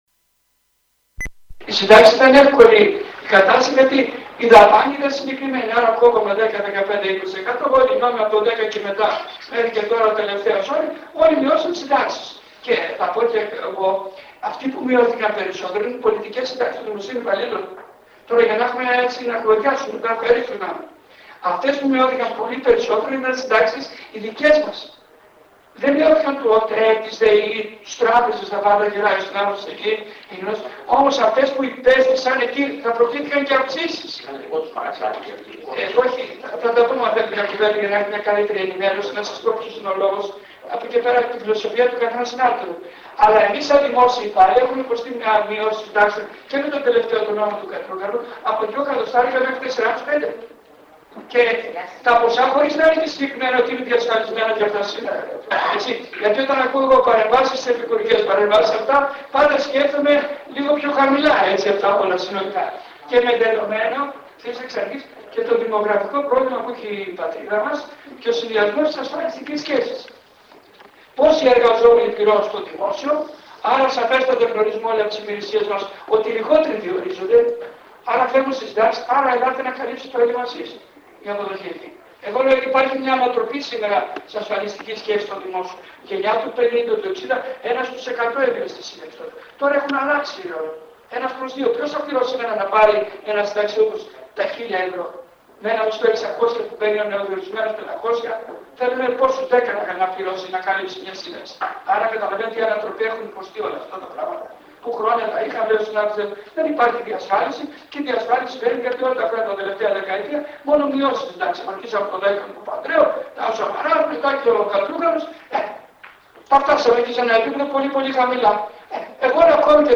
Συζήτηση και Ενημέρωση για το Συνταξιοδοτικό και Ασφαλιστικό καθεστώς των Δημοσίων υπαλλήλων έγινε χθές 16/11/19 στην αίθουσα του Ξενοδοχείου Καβαλιέρι απο